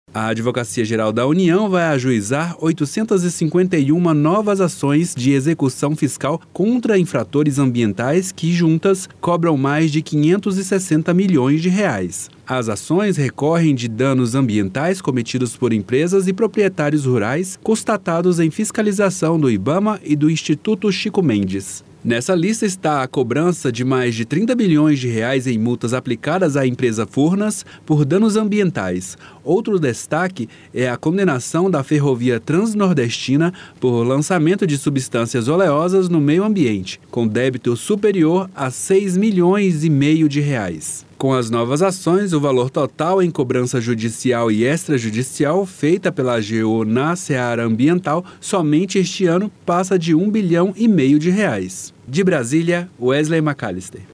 Ouça o boletim e saiba mais.